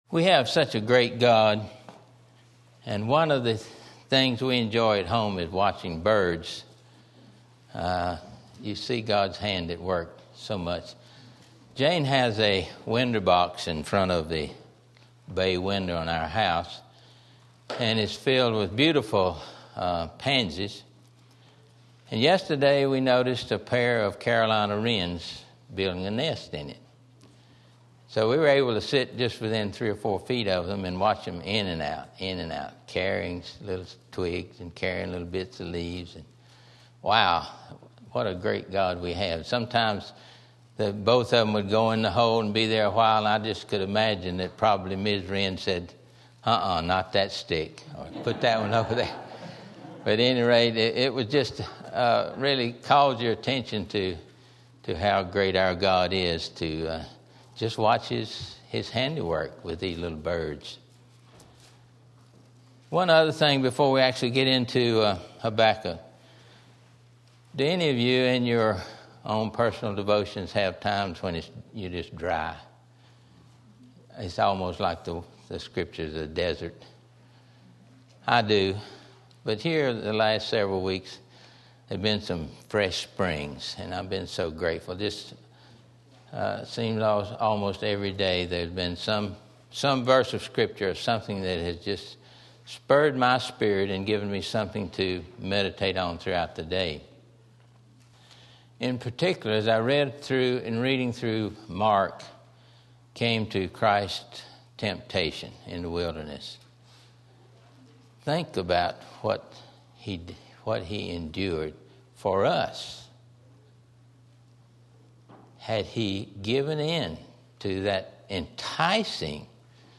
The Life of Faith Habakkuk Sunday School